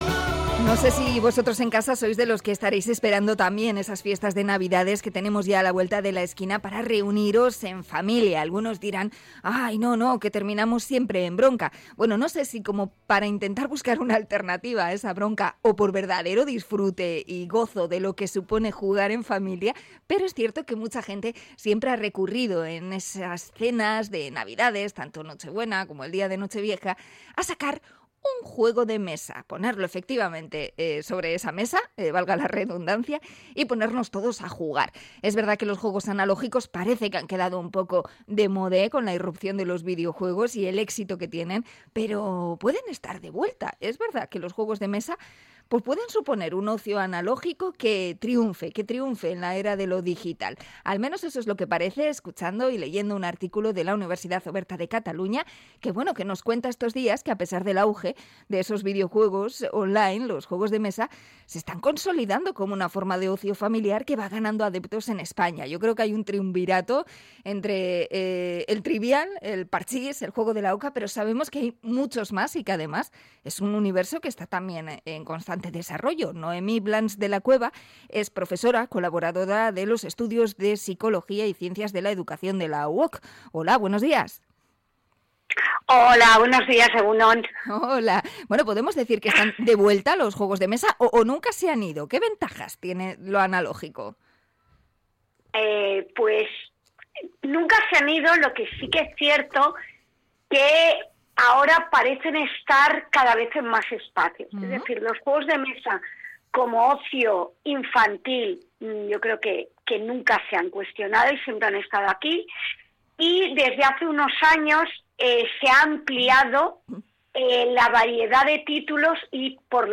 Entrevista a experta en juegos de mesa